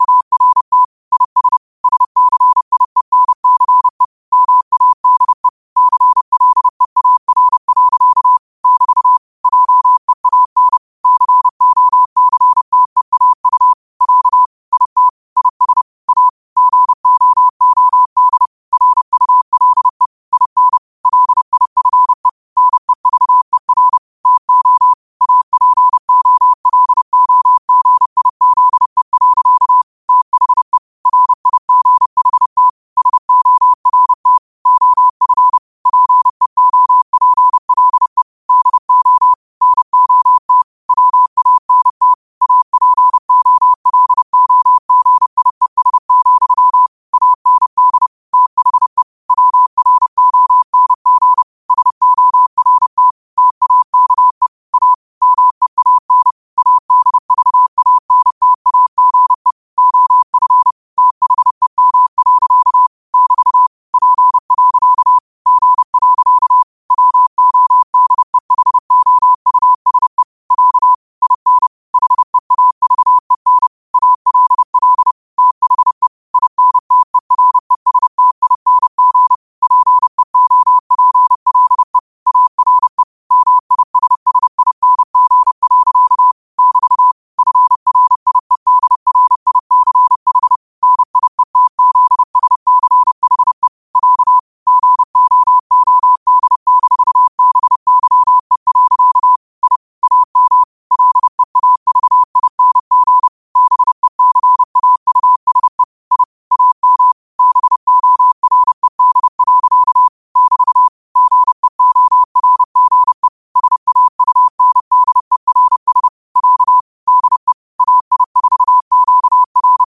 New quotes every day in morse code at 30 Words per minute.